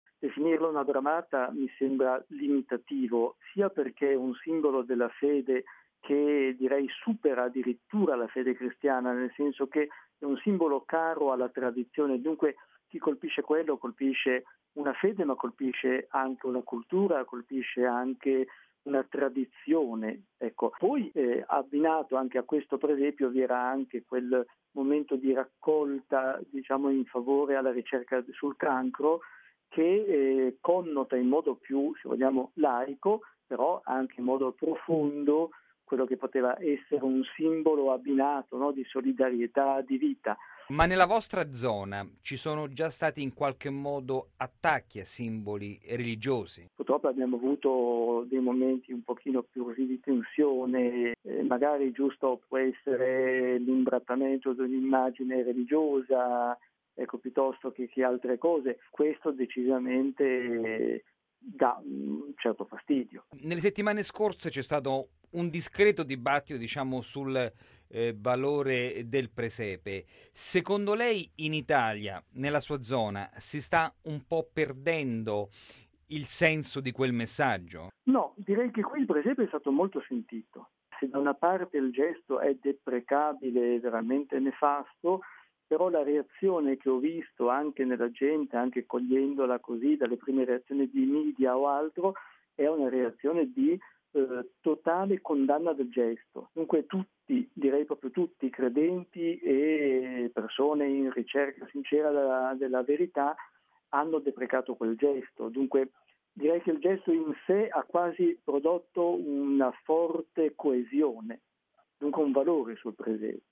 Un brutto gesto, ma che ha unito credenti e non credenti nel condannarlo: è quanto ha detto il vescovo della Spezia, mons. Luigi Ernesto Palletti.